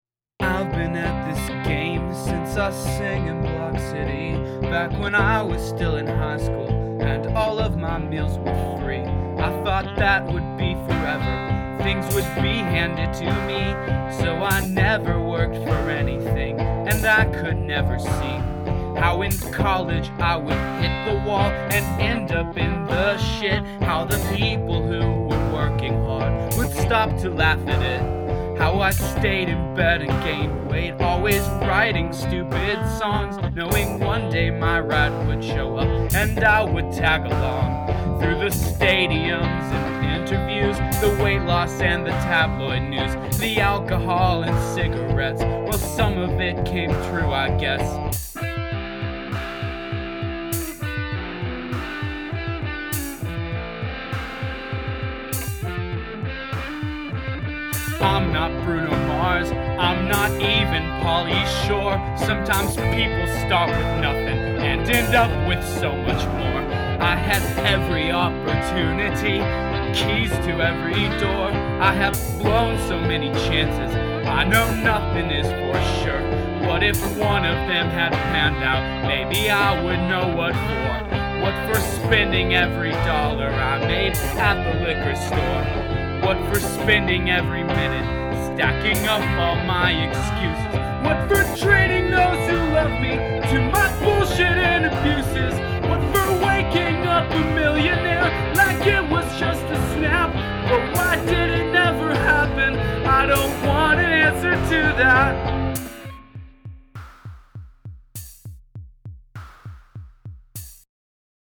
You've got some really awkward scansion, emphasizing the wrong syllables in a word or the wrong word in a sentence.